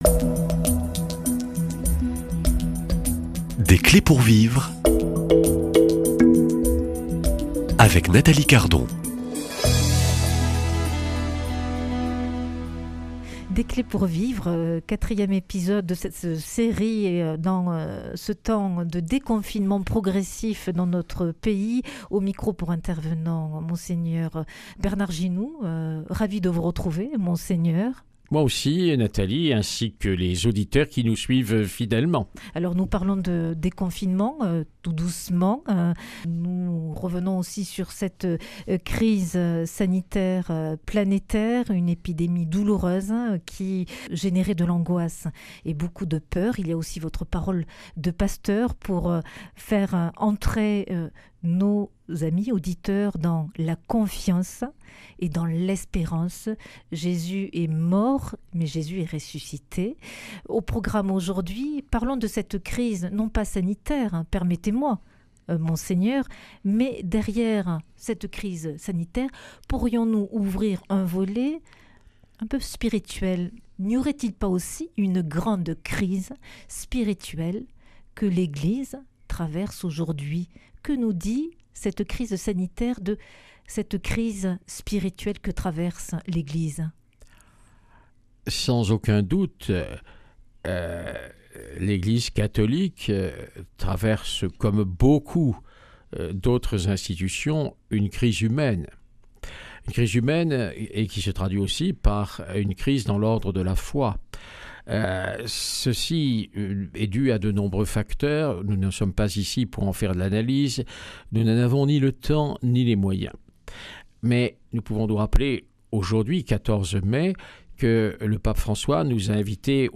Crise sanitaire, humaine, spirituelle... Invité : Mgr Bernard Ginoux (évêque du diocèse de Montauban)